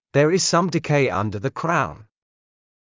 ｾﾞｱ ｲｽﾞ ｻﾑ ﾃﾞｨｹｲ ｱﾝﾀﾞｰ ｻﾞ ｸﾗｳﾝ